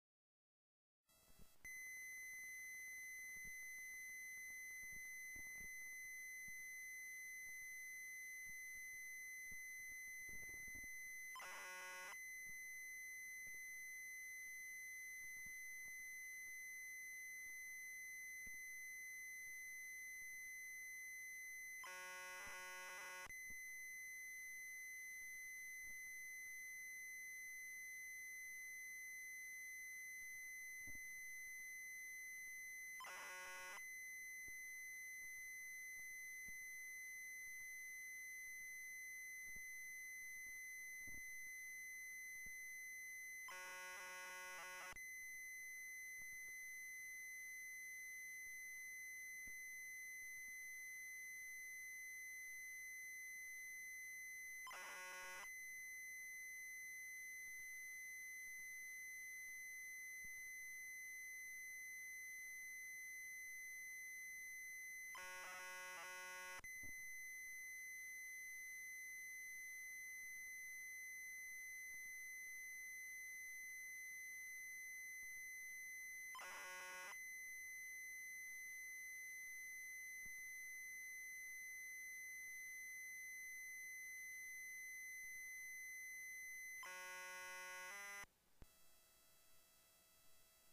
It seems to only send audio over the left channel.
;D. The blocks are really short and the IRGs (Inter-Record Gaps) are really long.
BTW I think I've found your problem: The recording is too damn low.
Also I can hear a faint electronic-like noise but that may be unavoidable.